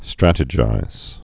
(strătə-jīz)